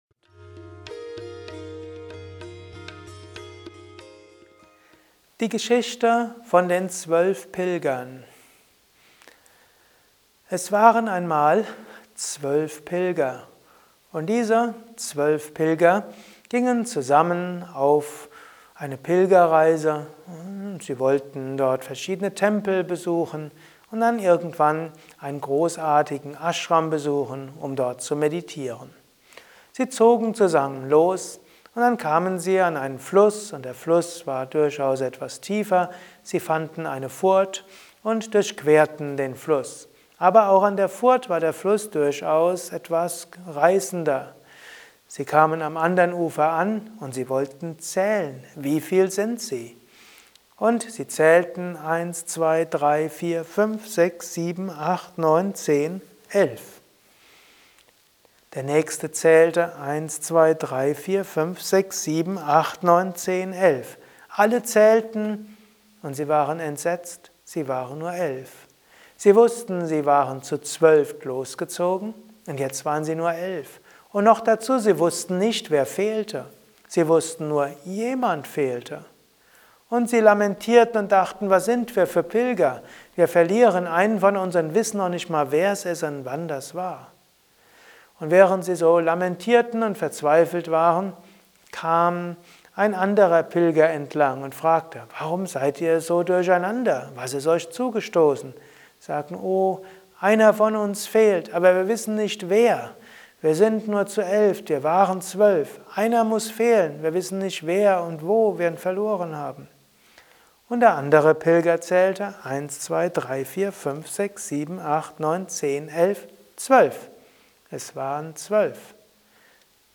erzählt die Vedanta Geschichte von den zwölf Pilgern.